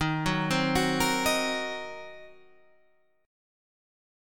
D# Minor 6th